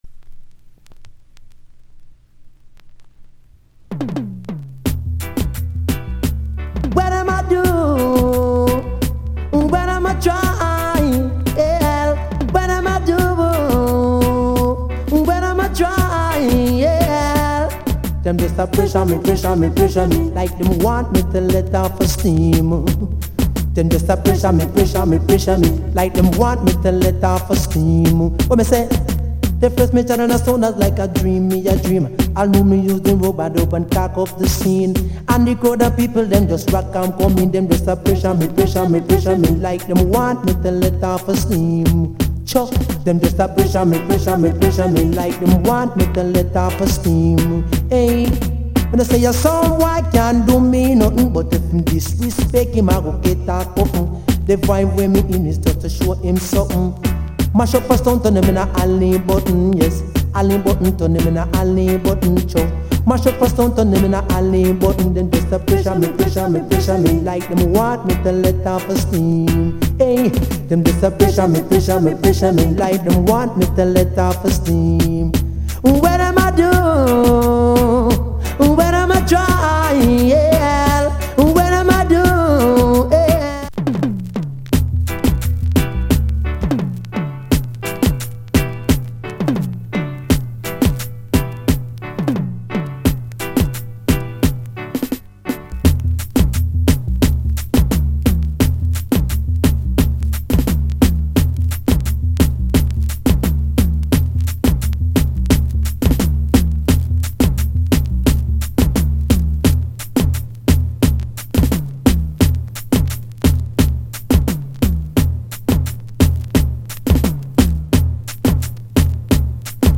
*Killer Dance Hall Vocal!